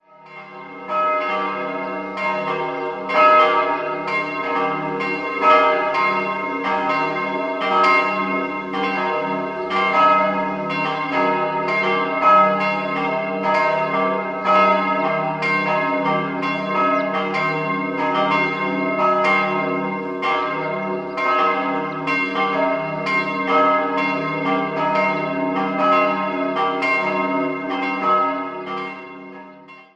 Beschreibung der Glocken
Außerdem schaffen die Gelbtöne an Wänden und Decke eine warme, angenehme Atmosphäre. 3-stimmiges Geläute: es'-g'-c'' Eine genaue Glockenbeschreibung folgt unten.